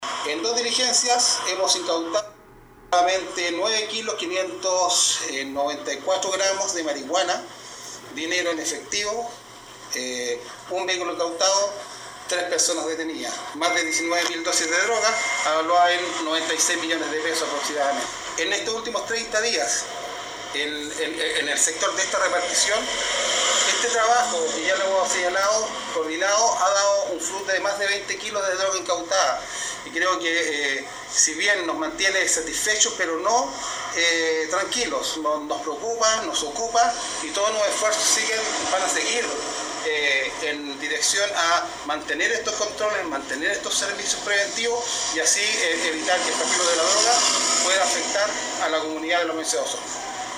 El oficial de Carabineros destacó este operativo que permitió detener a los sujetos, quienes pretendían comercializar la marihuana en la provincia de Chiloé.
15-CORONEL-GUSTAVO-SAAVEDRA-PREFECTO-2.mp3